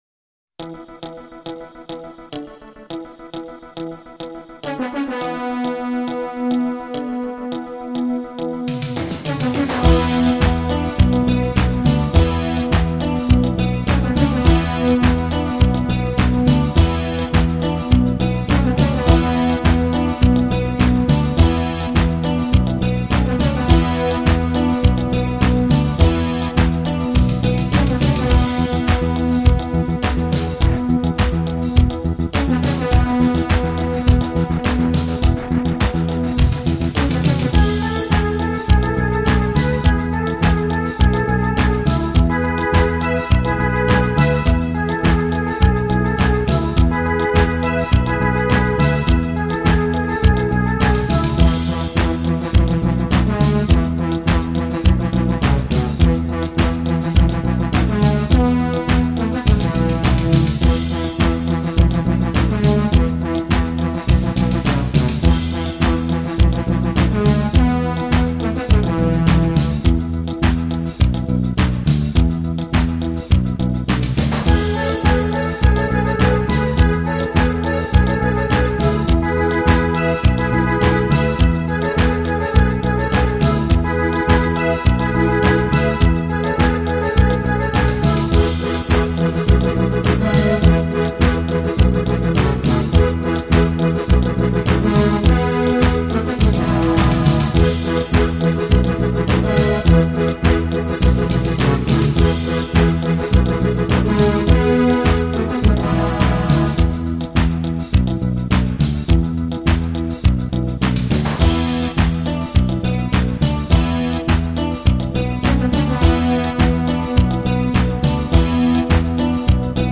танцевальная песня